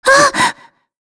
Shea-Vox_Damage_01.wav